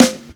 rue_snr_1.wav